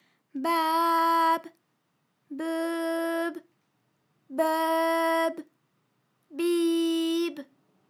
ALYS-DB-001-FRA - First, previously private, UTAU French vocal library of ALYS
babebeubib.wav